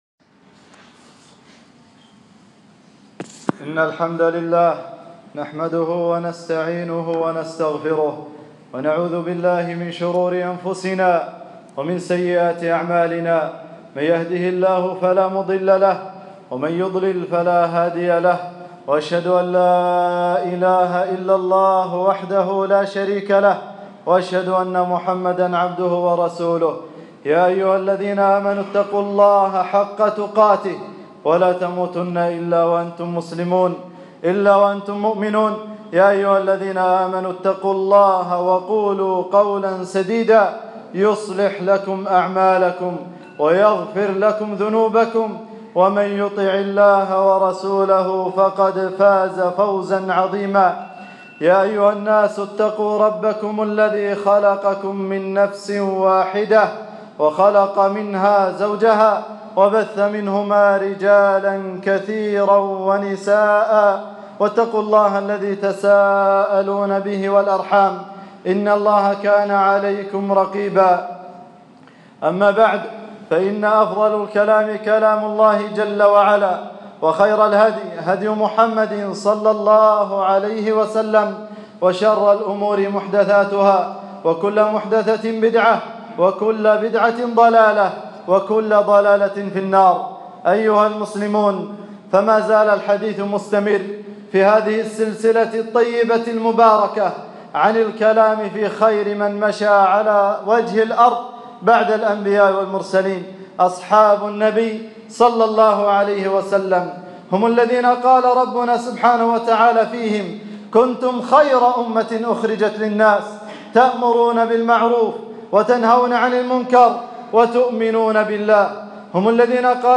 خطبة - فضائل أبي بكر الصديق 3-2-1440